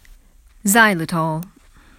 発音に注意してください。